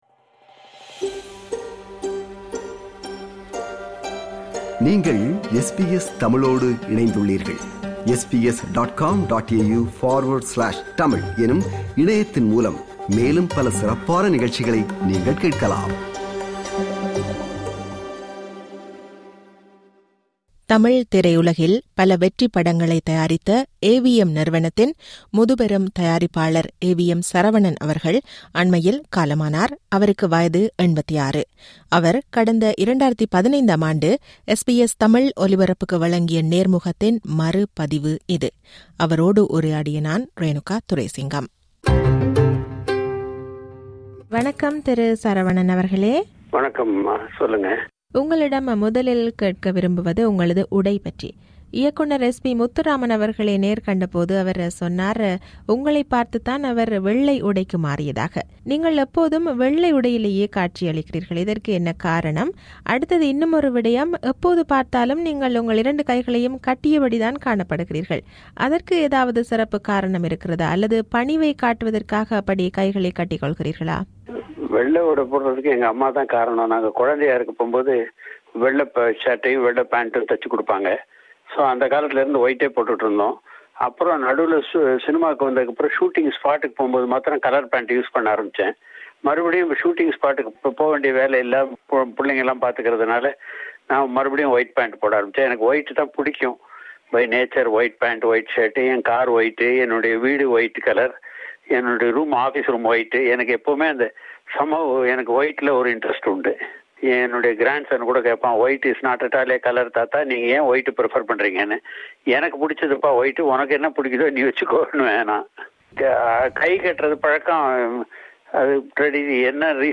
மறைந்த முதுபெரும் தயாரிப்பாளர் AVM சரவணன் வழங்கிய நேர்முகம்!
அவர் கடந்த 2015ம் ஆண்டு SBS தமிழ் ஒலிபரப்புக்கு வழங்கிய நேர்முகத்தின் மறுபதிவு இது.